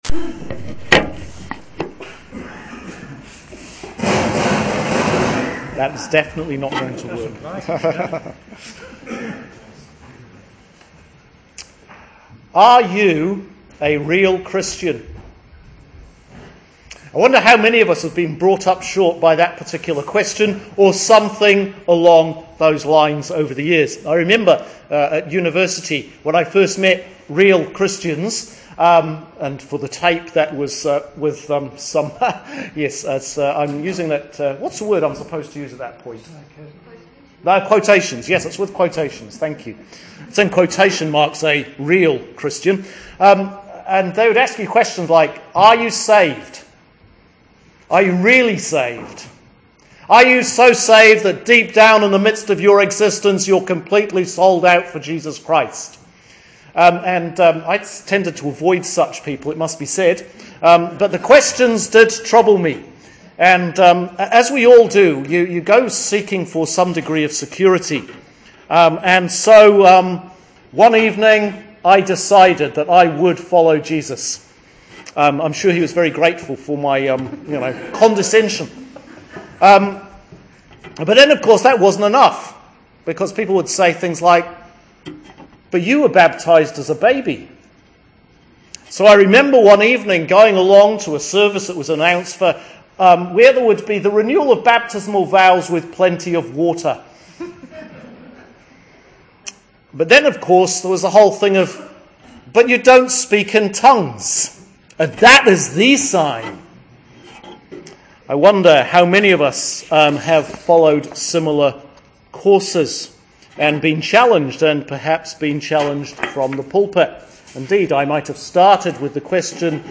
Sermon for Easter 5 – Sunday 28th April – Year C